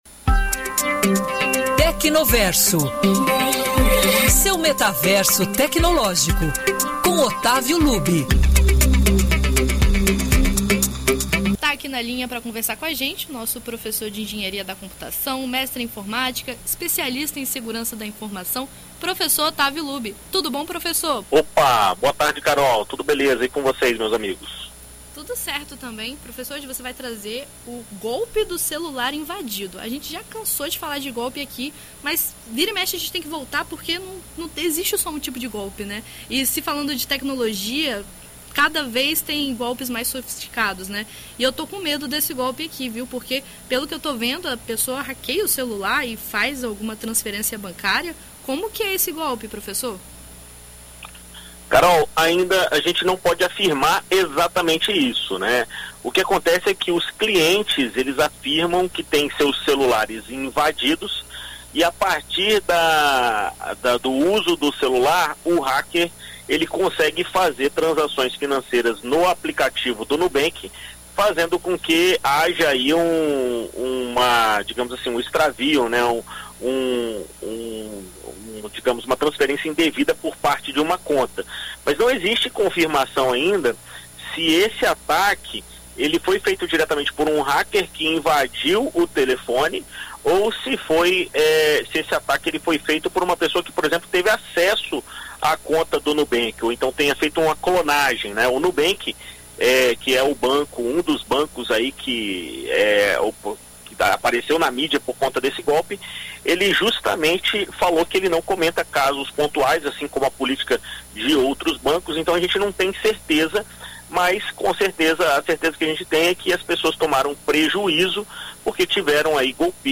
Em entrevista à BandNews FM Espírito Santo nesta terça-feira (18)